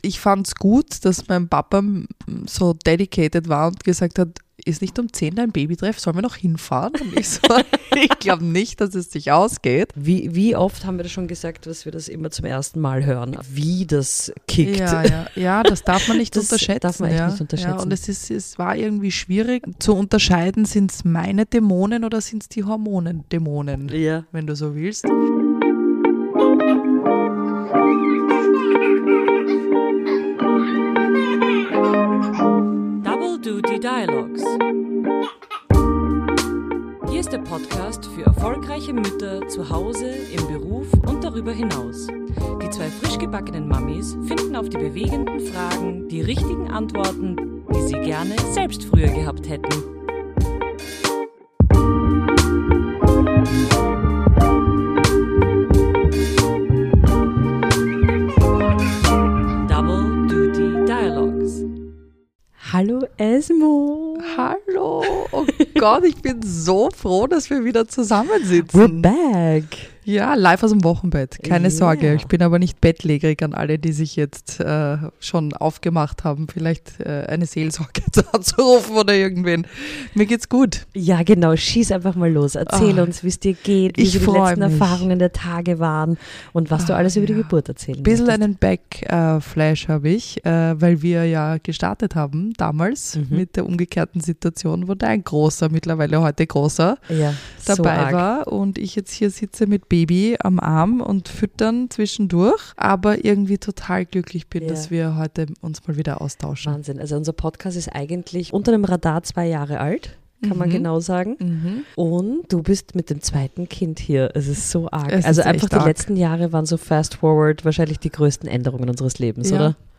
Es wird viel gelacht, ein bisschen geschnauft und sehr ehrlich hingeschaut, was Mutterschaft mit dem Kopf so anstellt.